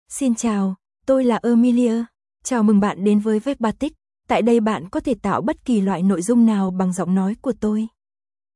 Amelia — Female Vietnamese (Vietnam) AI Voice | TTS, Voice Cloning & Video | Verbatik AI
Amelia is a female AI voice for Vietnamese (Vietnam).
Voice sample
Listen to Amelia's female Vietnamese voice.
Amelia delivers clear pronunciation with authentic Vietnam Vietnamese intonation, making your content sound professionally produced.